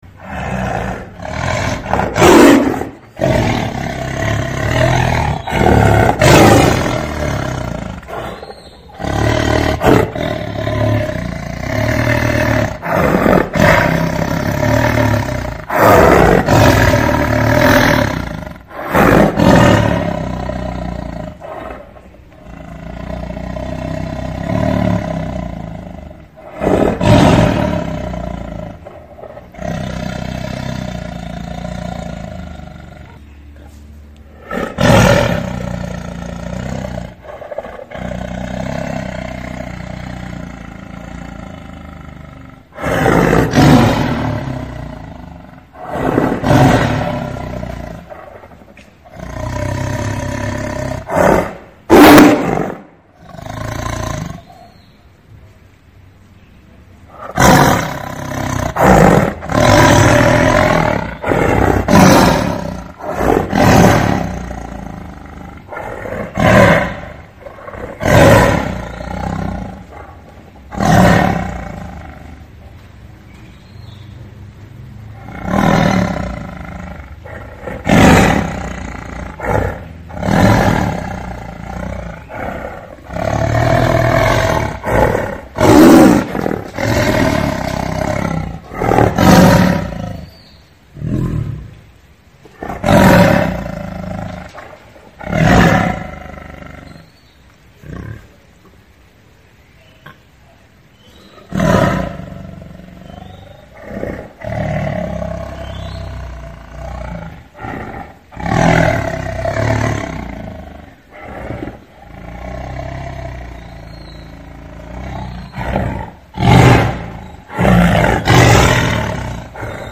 เสียงเสือร้อง เสือขู่
หมวดหมู่: เสียงสัตว์ป่า
tieng-ho-gam-www_tiengdong_com.mp3